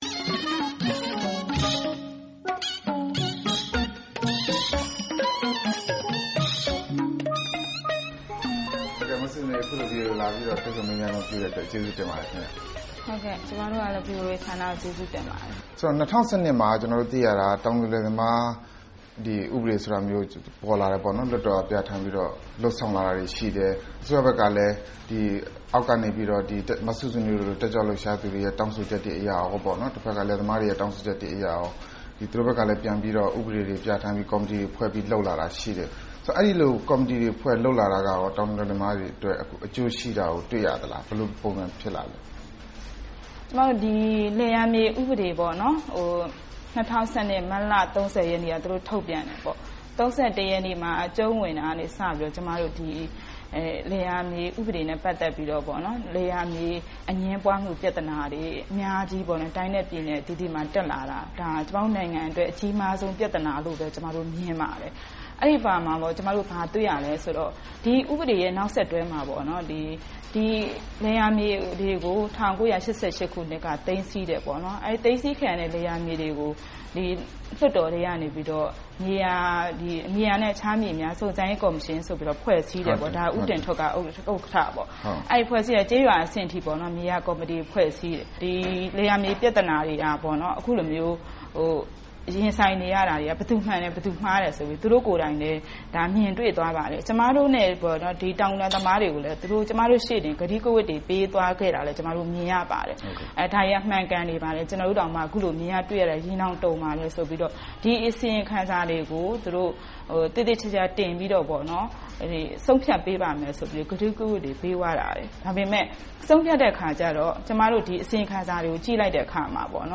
မစုစုနွေးနဲ့ တွေ့ဆုံမေးမြန်းခန်း